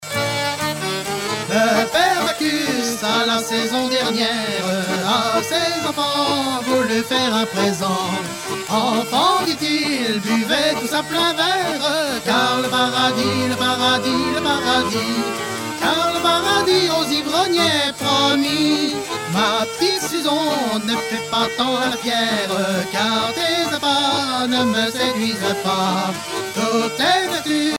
circonstance : bachique
Genre strophique
Pièce musicale éditée